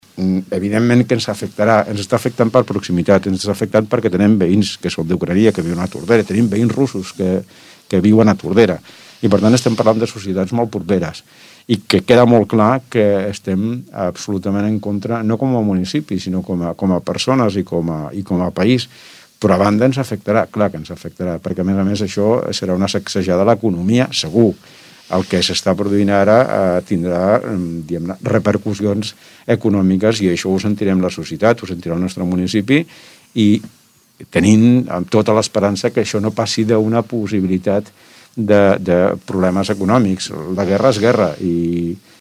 L’Alcalde de Tordera, Joan Carles Garcia explica que la guerra ens afecta i que no podem girar-hi l’esquena.